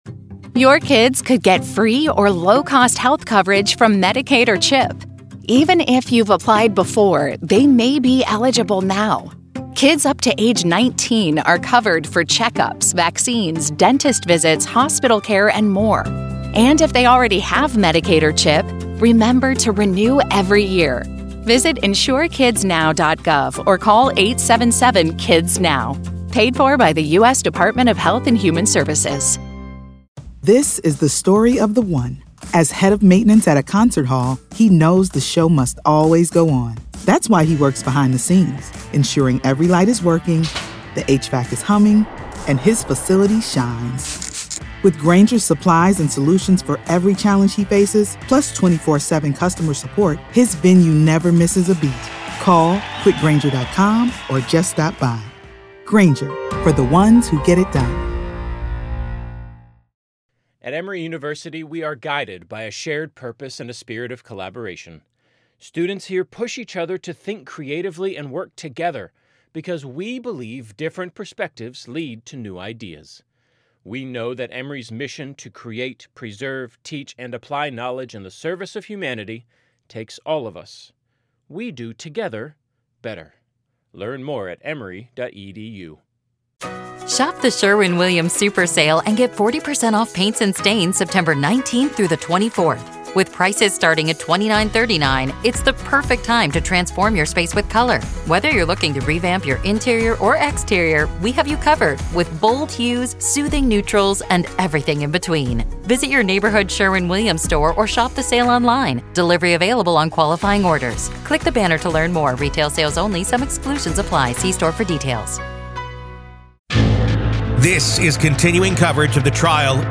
SOME LANGUAGE MAY BE OFFENSIVE:
Headliner Embed Embed code See more options Share Facebook X Subscribe SOME LANGUAGE MAY BE OFFENSIVE: Welcome to a special episode of "The Trial of Karen Read," where today, we find ourselves inside the courtroom of the case against Karen Read.